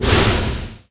poison_cloud.wav